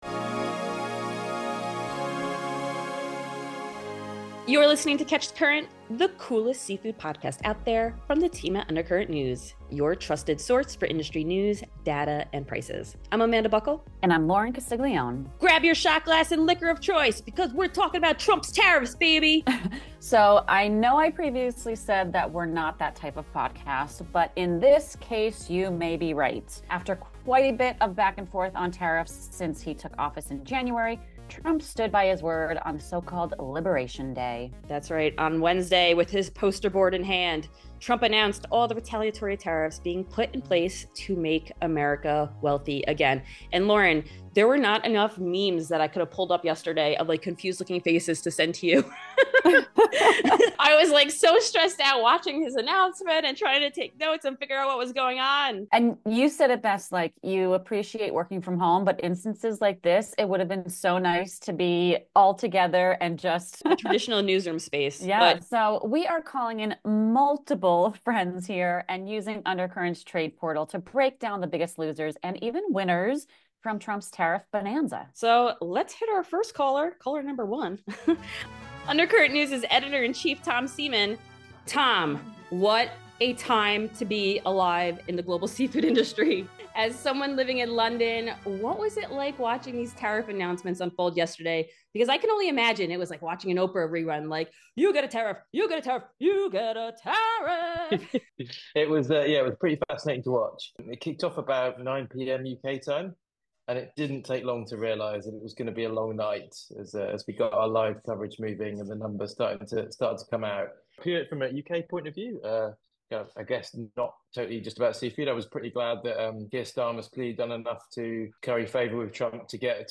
(In our best Oprah impression) 'You get a tariff! You get a tariff! You get a tariffff!!!!!'